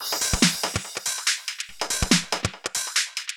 Index of /musicradar/uk-garage-samples/142bpm Lines n Loops/Beats
GA_BeatAFilter142-11.wav